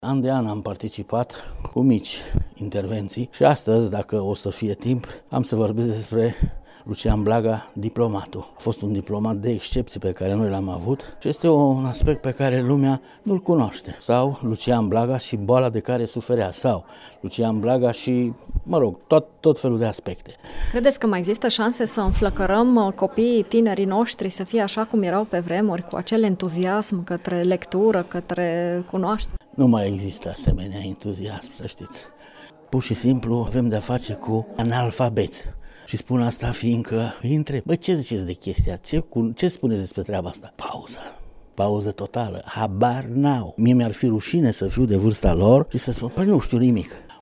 A XVIII-a ediție a Festivalului Internațional ,,Lucian Blaga”, organizat de Societatea Scriitorilor Mureșeni și Editura Ardealul, a debutat, vineri, la Radio România Tg.Mureș.